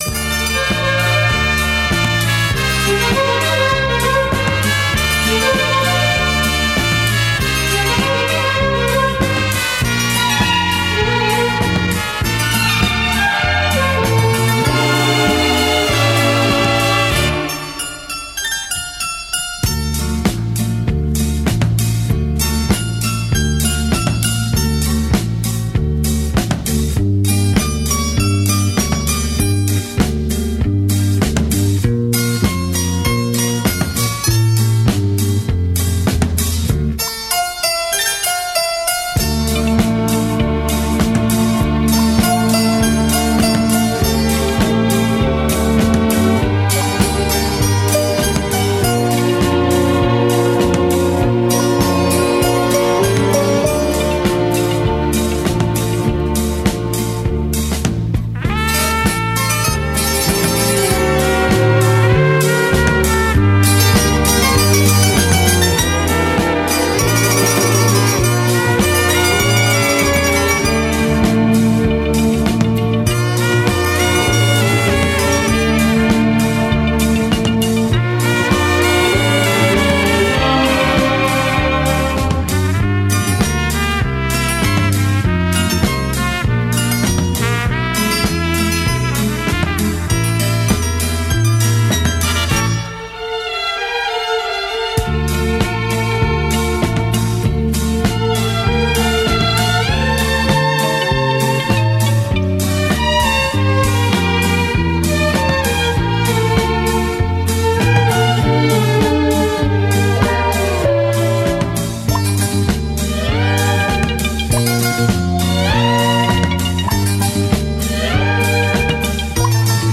но это моно..............